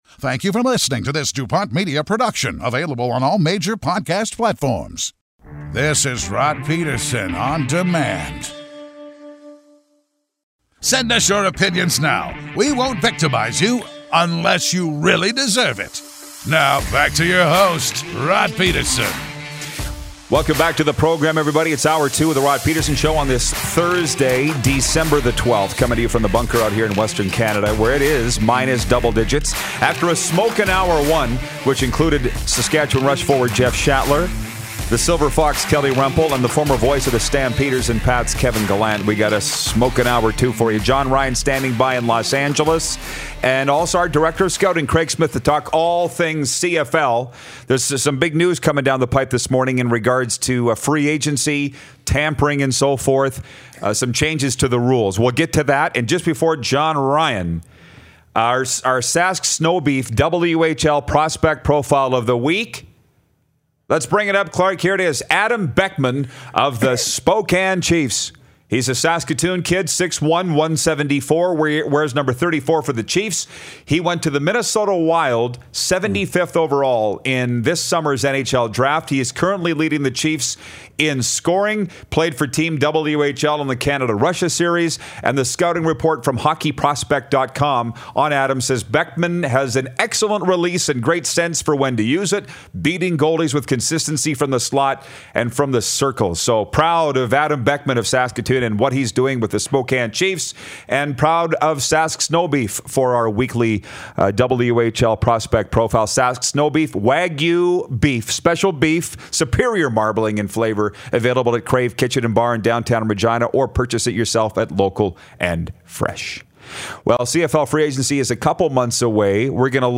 We’re firing up the sports chat on a Thursday morning, grab your coffee and join us!
Jon Ryan, Saskatchewan Roughriders Punter, gives us a call!